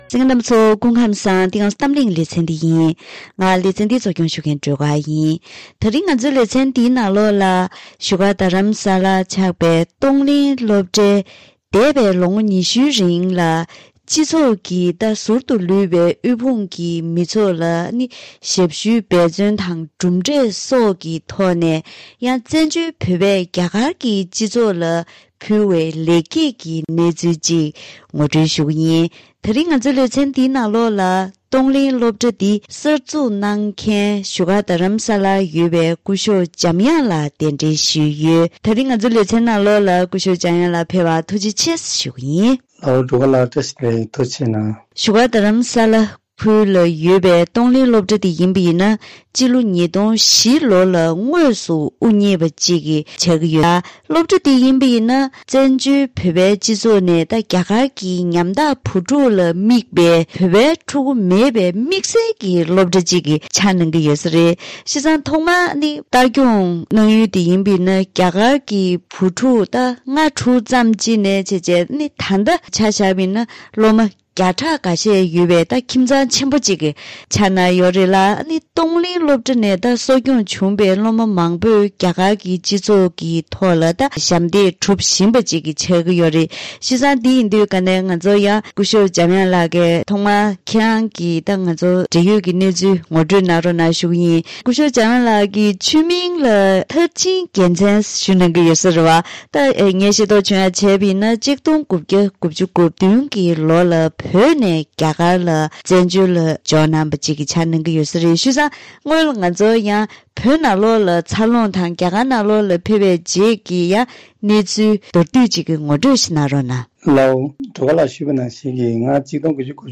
བཀའ་མོལ་ཞུས་པ།